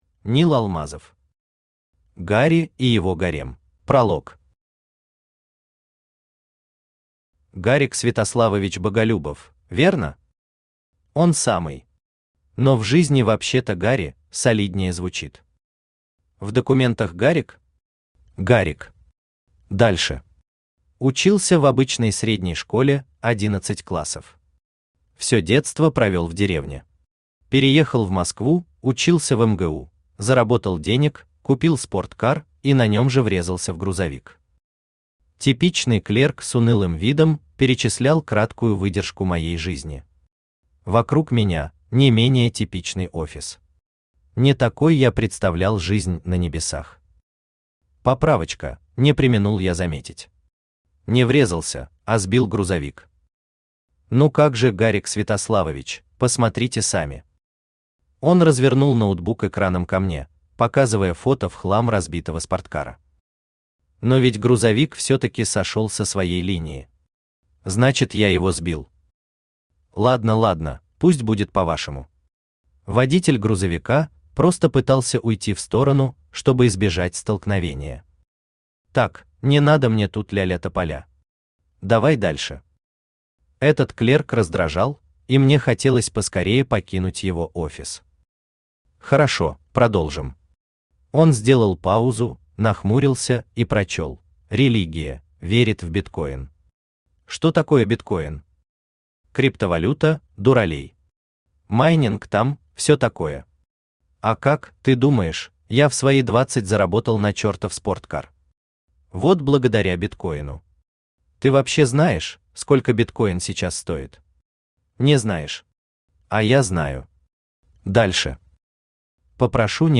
Аудиокнига Гарри и его гарем | Библиотека аудиокниг
Aудиокнига Гарри и его гарем Автор Нил Алмазов Читает аудиокнигу Авточтец ЛитРес.